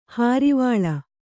♪ hārivāḷa